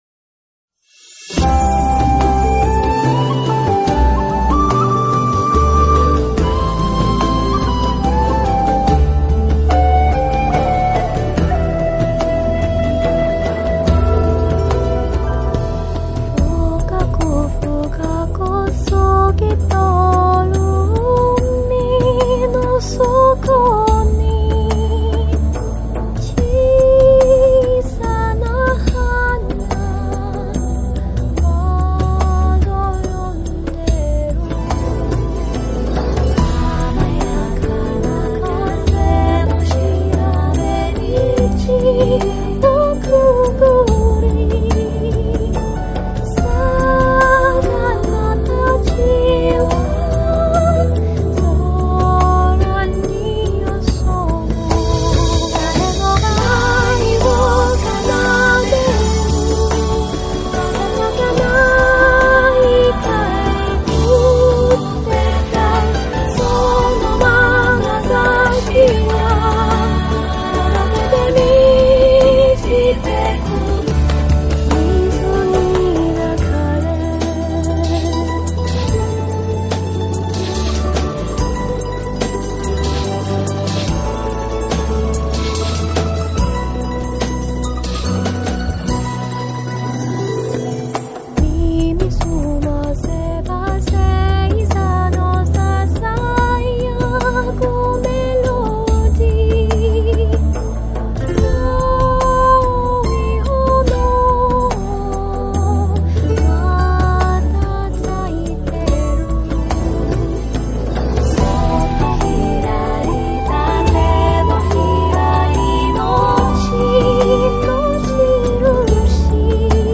Listen to me sing songs that come from cartoons or games.
Full performance; singing an anime's closing theme song